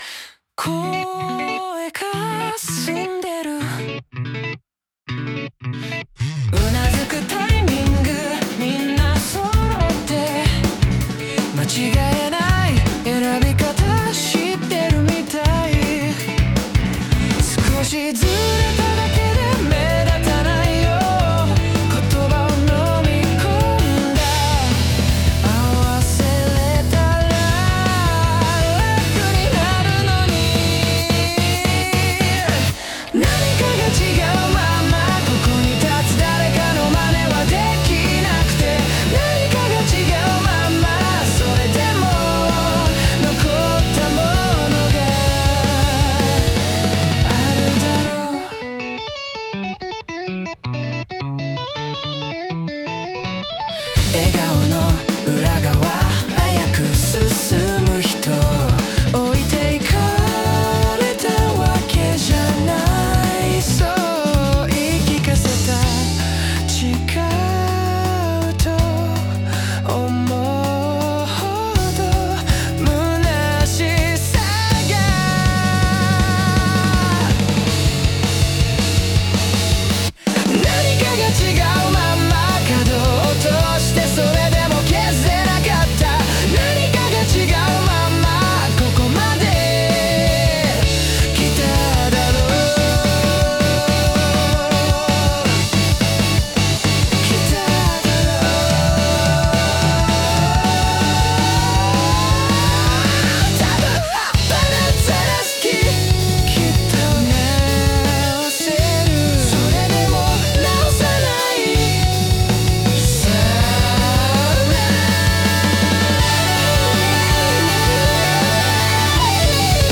男性ボーカル
イメージ：プログレッシブ・ロック,J-POP,男性ボーカル,かっこいい,切ない,エモい